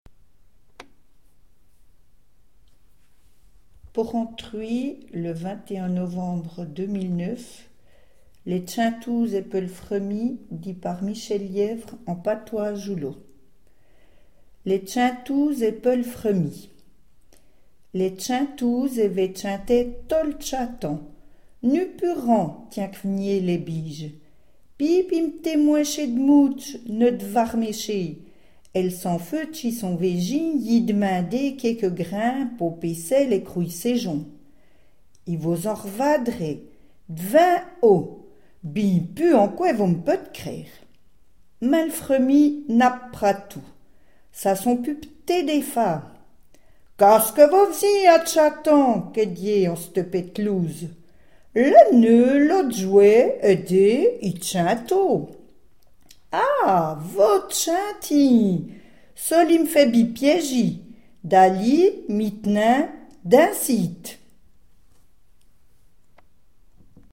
patois d’Ajoie